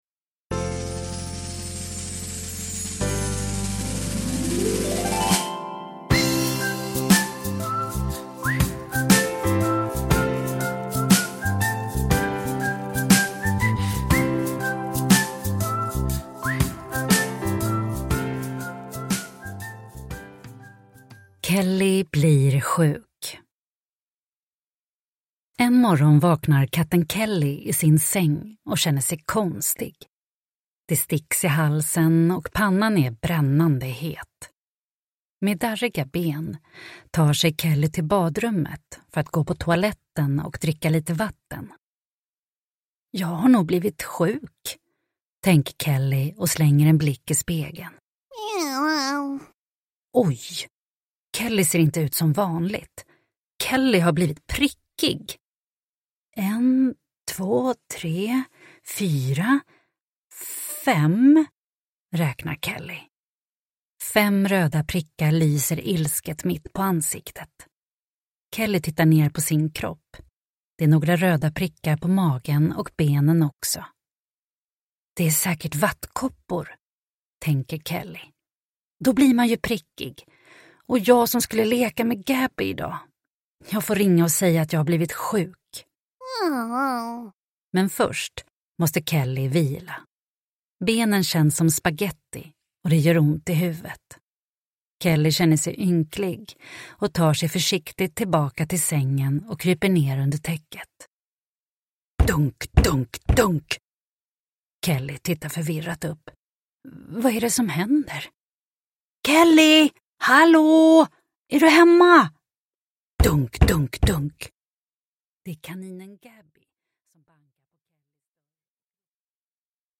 Kelly blir sjuk – Ljudbok – Laddas ner